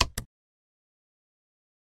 Use Inventory Object Sound.wav